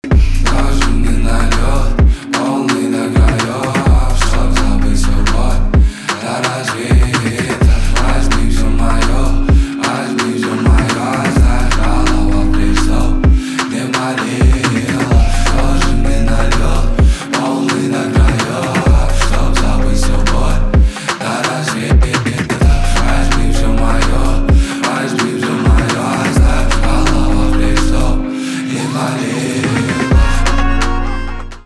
альтернатива
битовые , басы , грустные